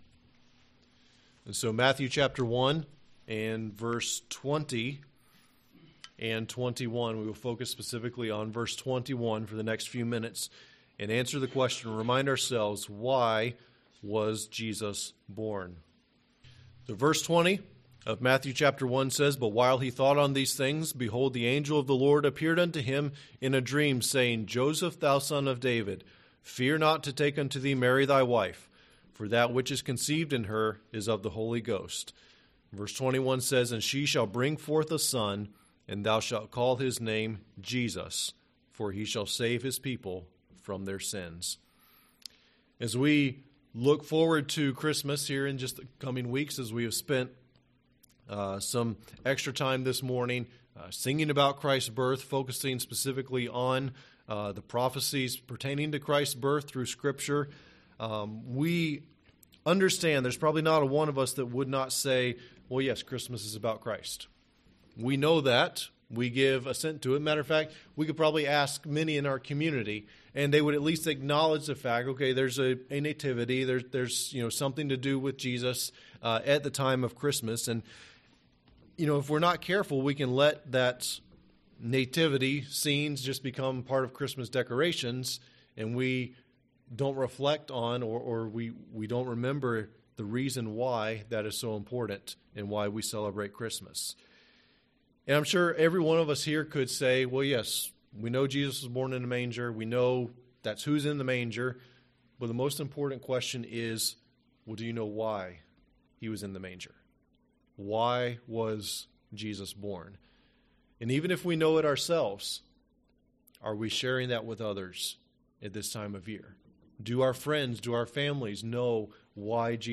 Sermons: Why Was Jesus Born?